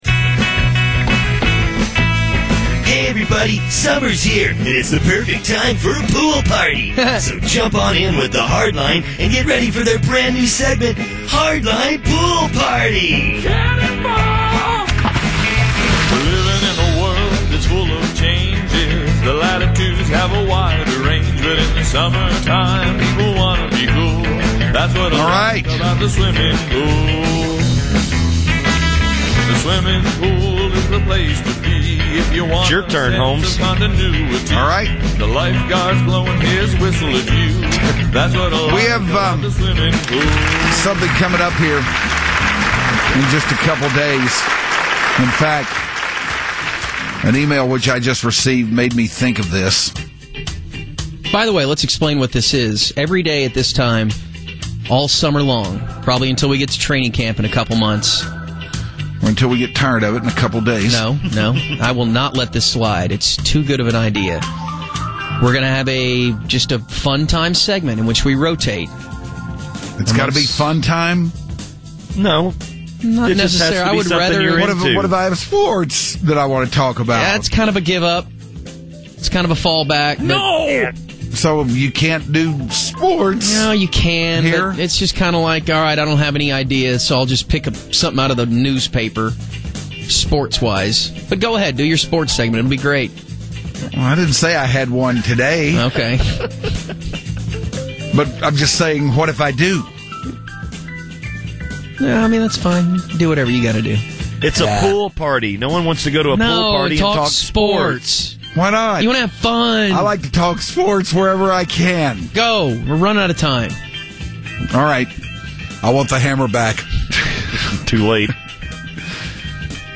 (Hint: it involves a lot of yelling…)